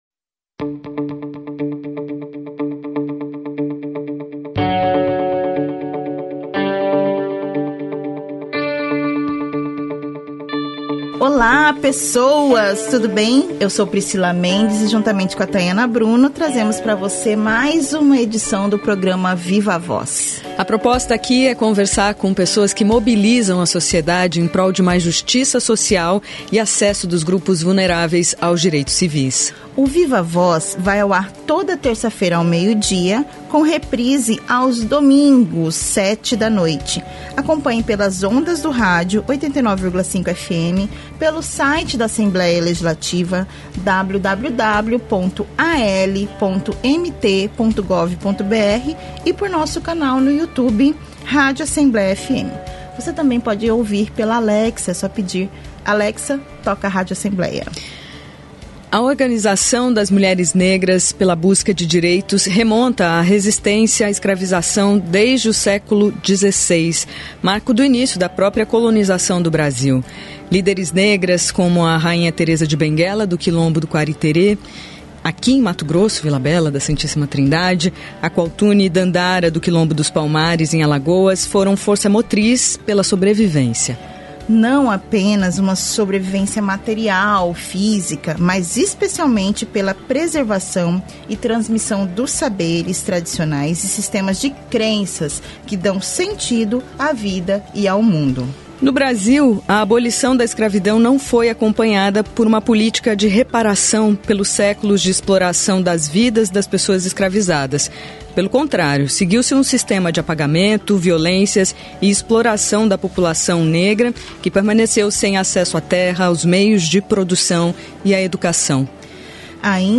entrevistam